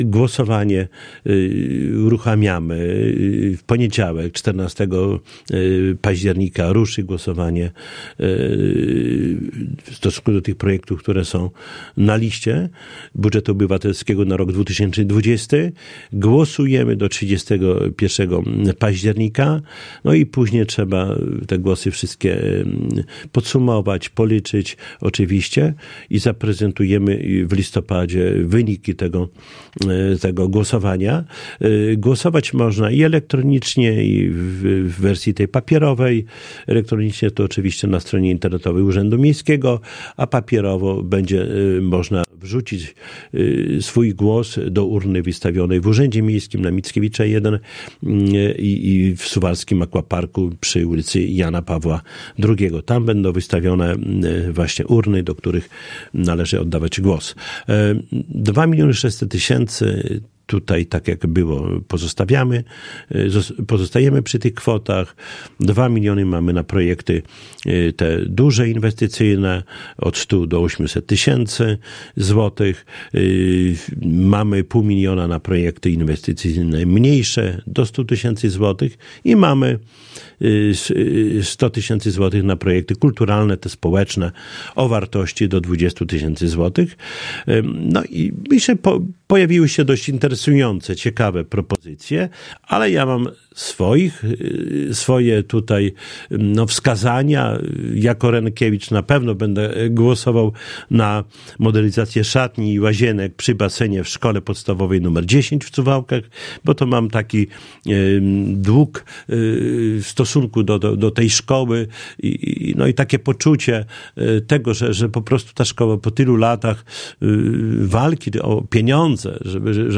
O szczegółach Suwalskiego Budżetu Obywatelskiego 2020 mówił w piątek (11.10) w Radiu 5 Czesław Renkiewicz, Prezydent Suwałk.